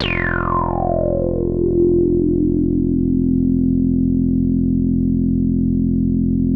BIG FLY.wav